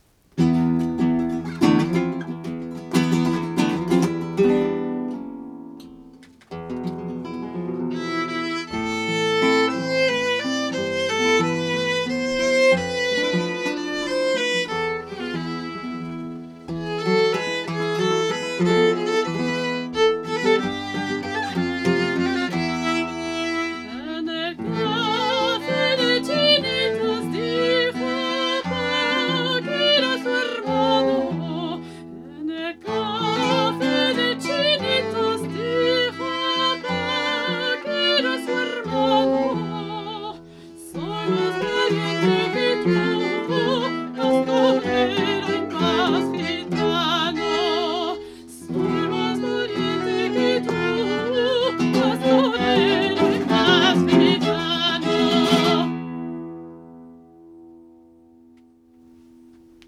guitare flamenca
violon
chant lyrique